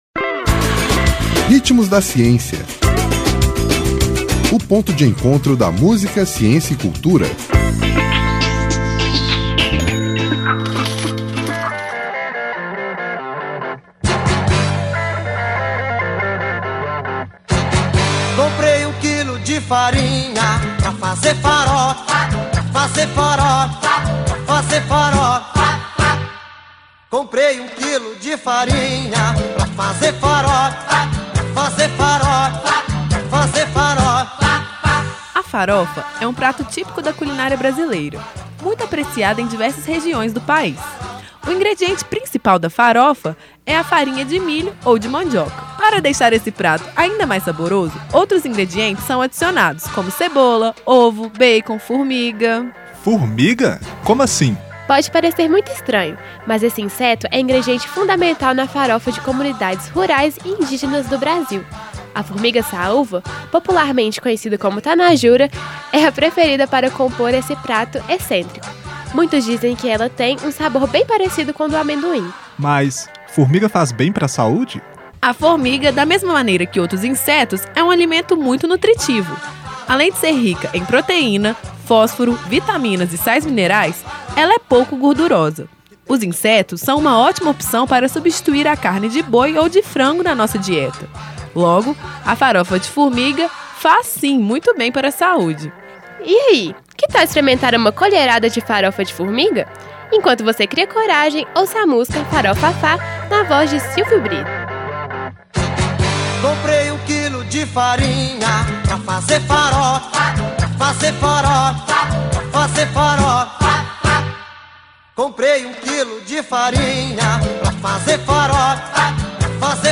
Intérprete: Silvio Brito